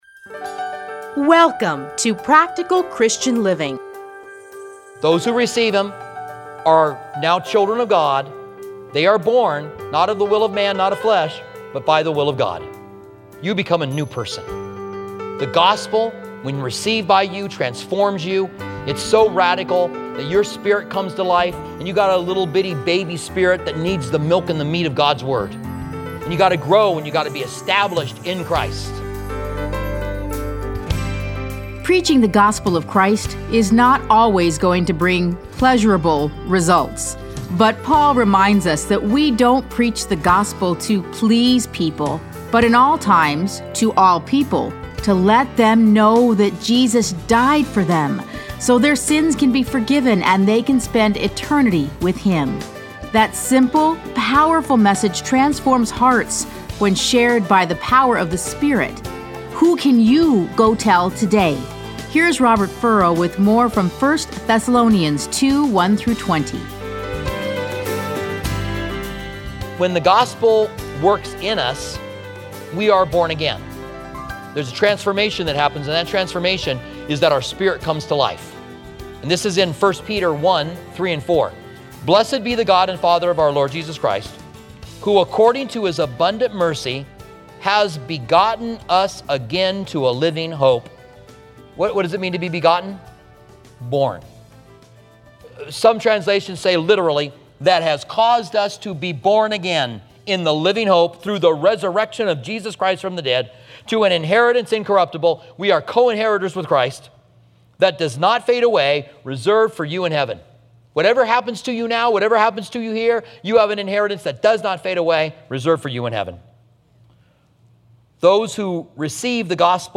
Listen to a teaching from 1 Thessalonians 2:1-20.